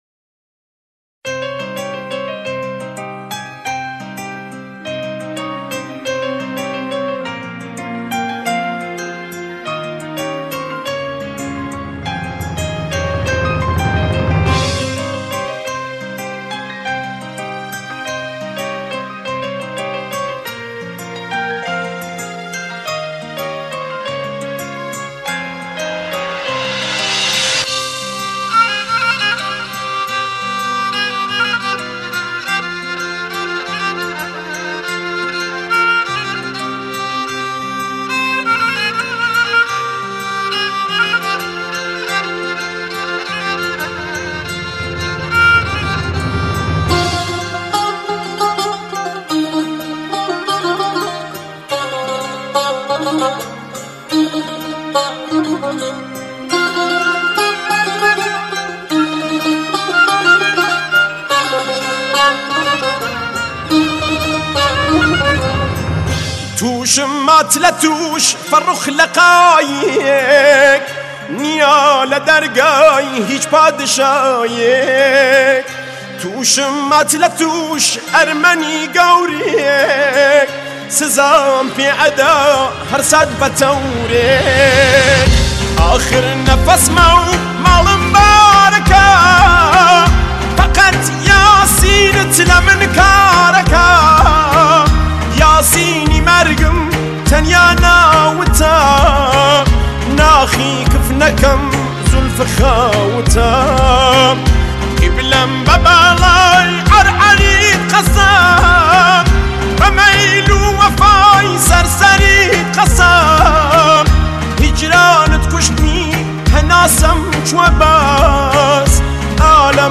اهنگ کردی